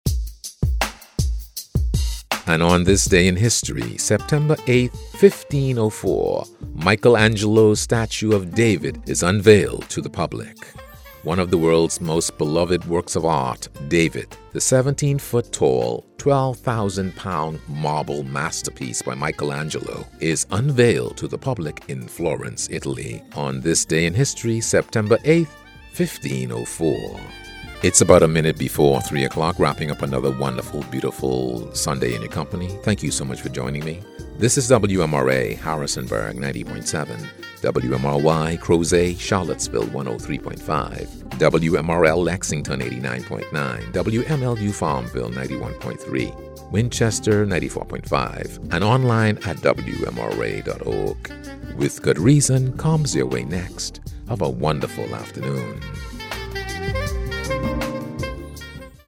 Englisch (Karibik)
Neumann U87
BaritonBassNiedrig
VertrauenswürdigUnternehmenAutorisierendSamtig